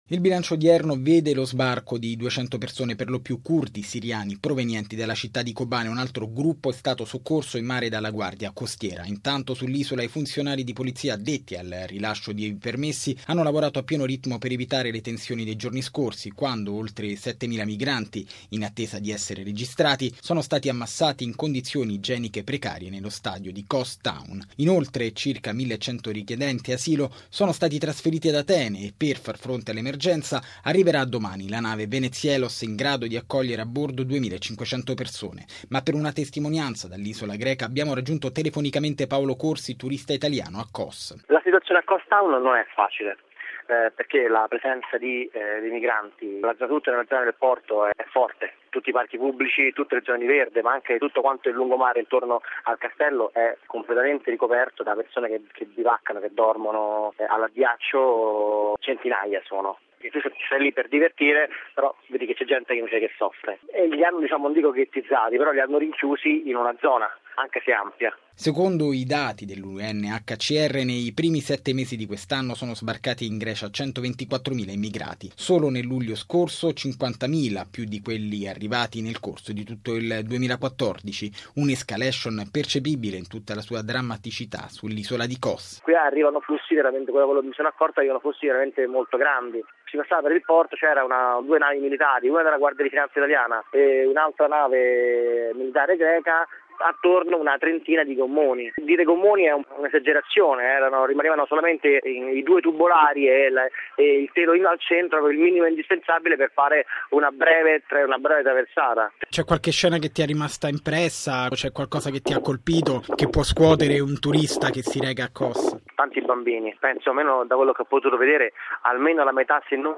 Ma per una testimonianza della situazione sull’isola greca abbiamo raggiunto telefonicamente